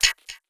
Perc (Crazy).wav